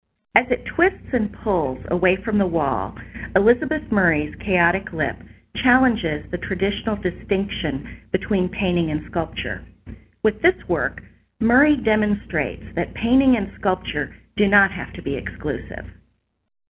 Audio Tour – Ear for Art